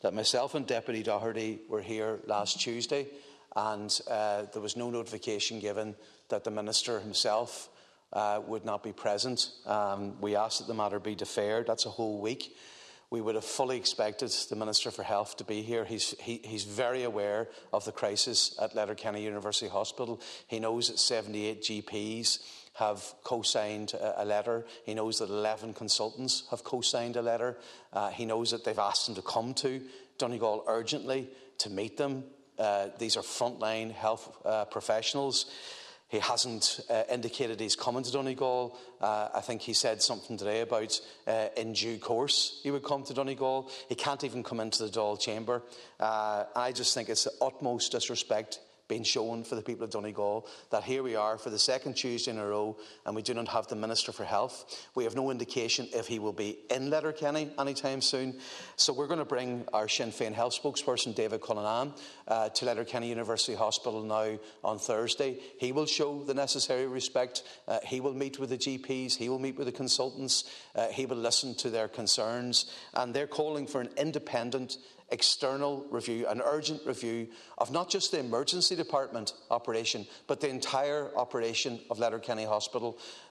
Tensions were high in the Dáil last night after Health Minister Stephen Donnelley failed to be in attendance for the second time for the Topical Issue on the need for an external review at Letterkenny University Hospital.
Deputy MacLochlainn said the Minister needs to set a date to visit Letterkenny, something he told the health professionals, who wrote him a letter outlining their concerns, that he would do: